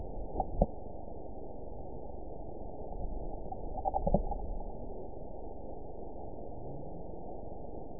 event 911570 date 03/03/22 time 17:07:52 GMT (3 years, 2 months ago) score 9.01 location TSS-AB05 detected by nrw target species NRW annotations +NRW Spectrogram: Frequency (kHz) vs. Time (s) audio not available .wav